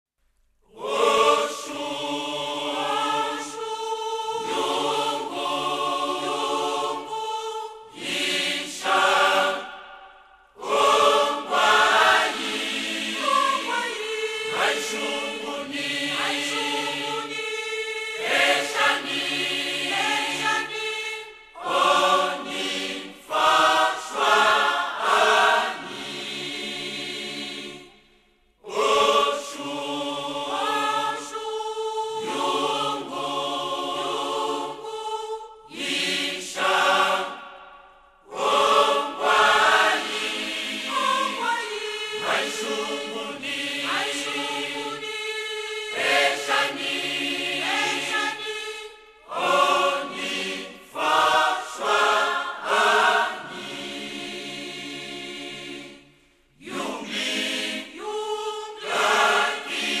Liste des cantiques